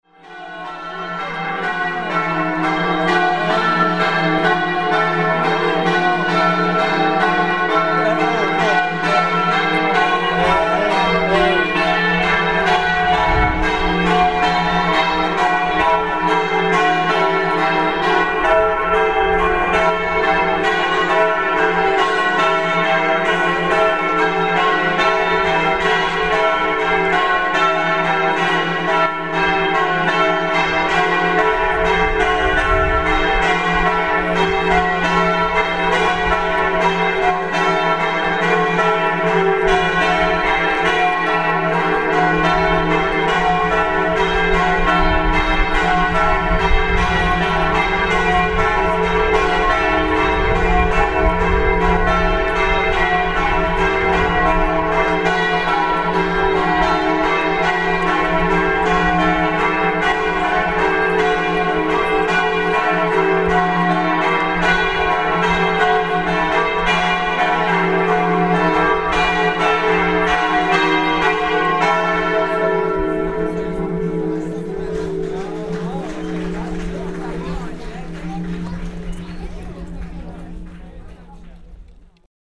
3D moving image of the Bande Musical can be seen here and a small MP3 file of the sound of the bells in Barga this morning can be heard
bells.mp3